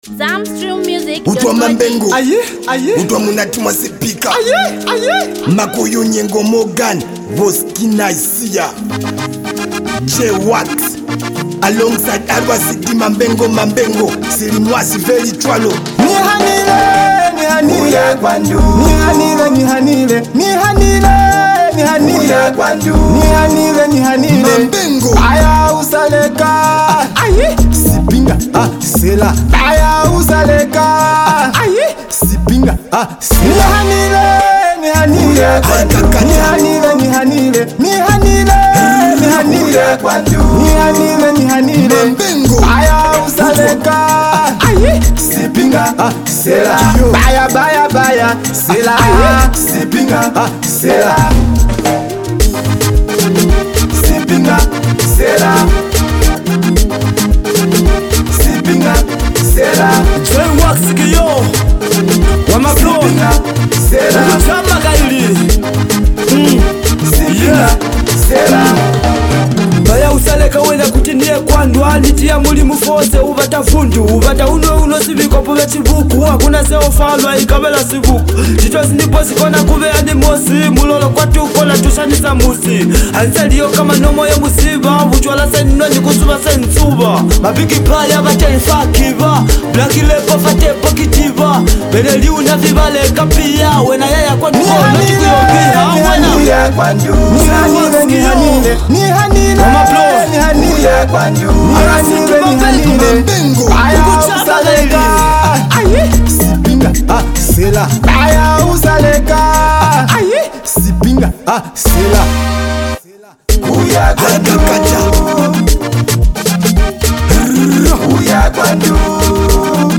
smooth melodies